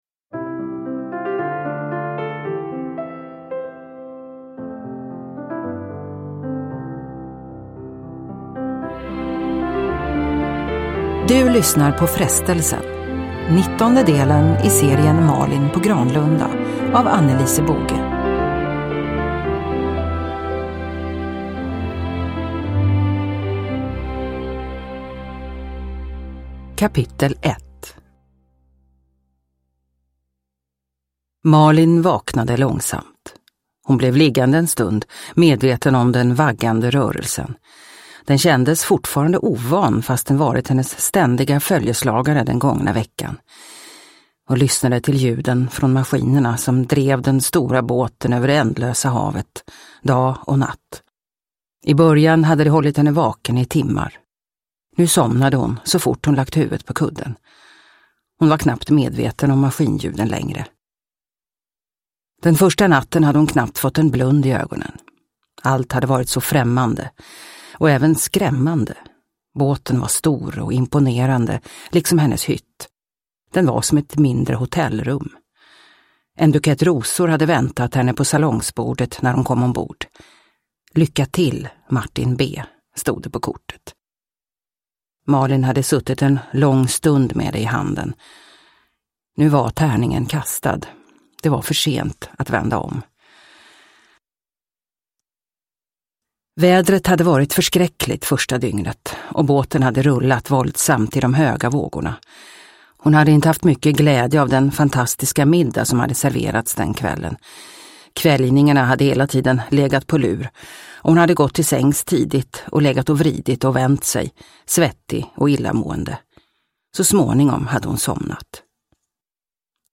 Frestelsen – Ljudbok – Laddas ner